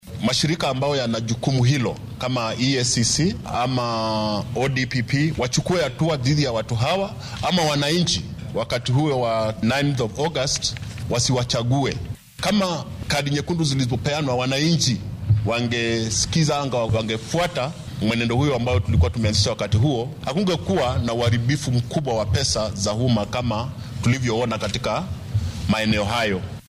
Mid ka mid ah mas’uuliyiinta shalay qabtay shirka jaraa’id ee ay baaqan ka soo saareen ayaa hadalkiisa sidatan u dhigay.